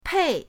pei4.mp3